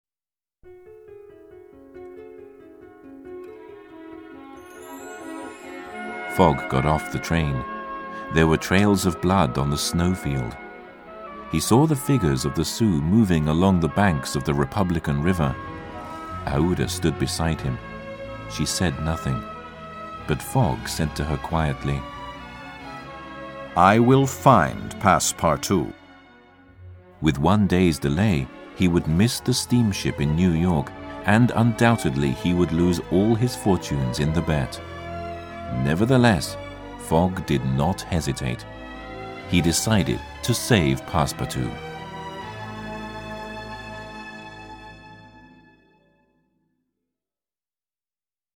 音声には効果音も取り入れていますので、学習者が興味を失わずに最後まで聴き続けることができます。